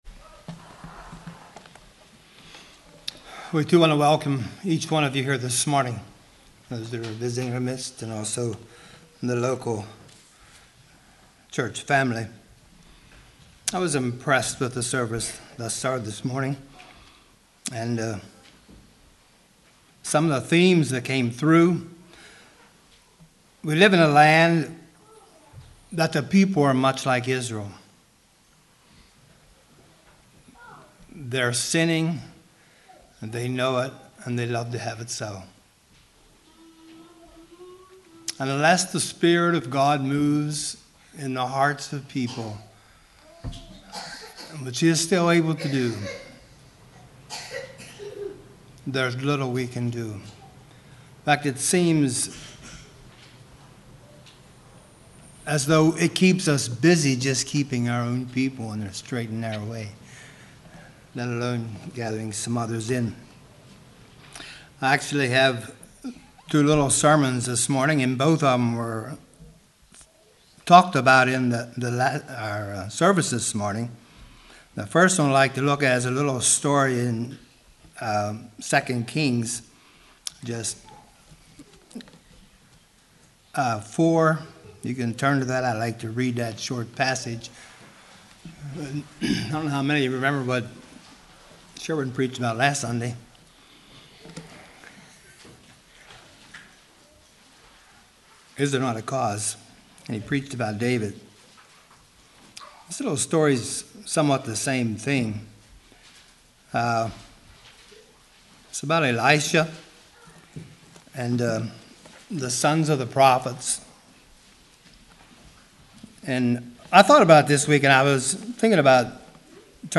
This is 2 sermons in one.... The Pre-Sermon is titled Death in the Pot and is taken from the story found in 2Kings 4:38-41.